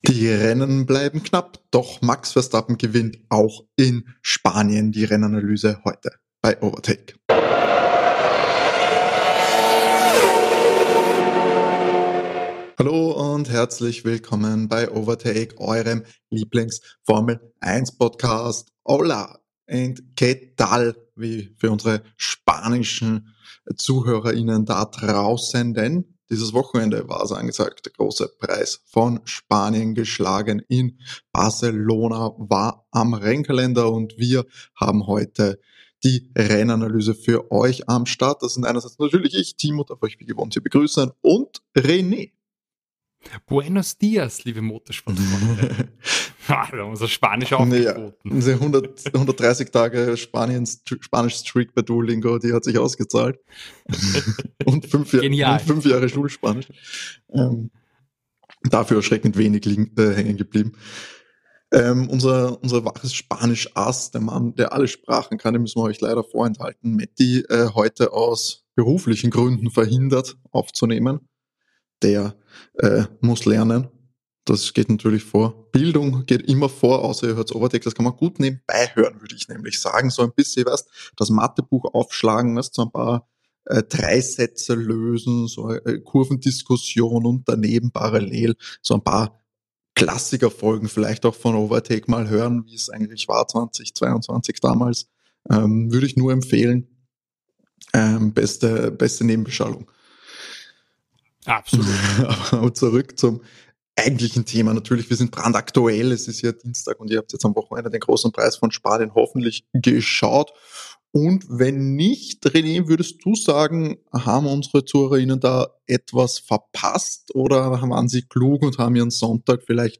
Erster Verfolger Lando Norris holt sich Platz 2 und Lewis Hamilton kann sich mit Platz 3 sein erstes Podium der Saison sichern. Wir bitten um Entschuldigung für die etwas schlechtere Tonqualität, ab nächster Woche sollte dies wieder behoben sein!